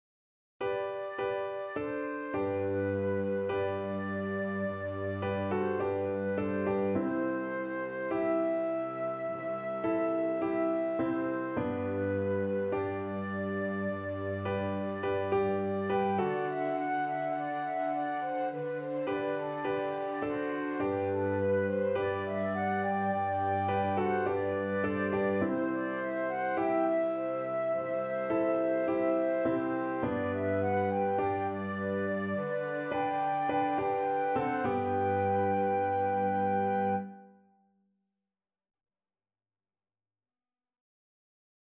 Freude am Herrn Kinderlied